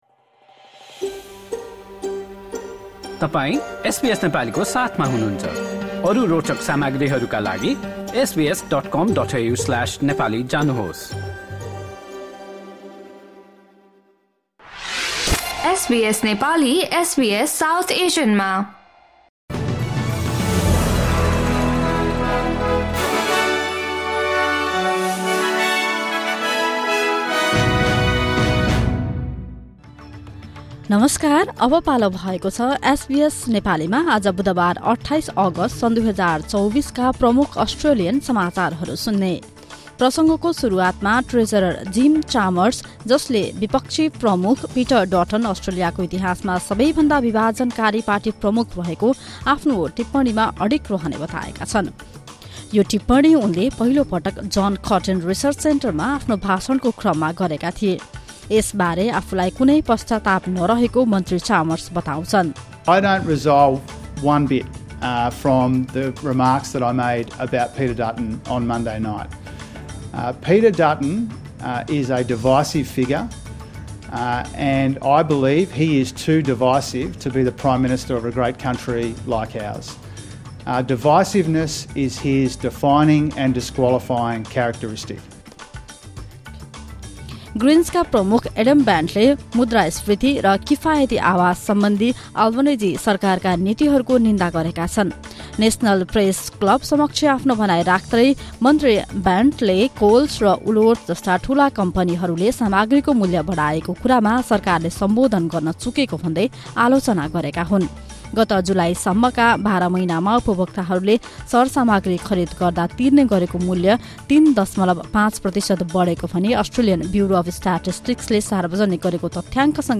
SBS Nepali Australian News Headlines: Wednesday, 28 August 2024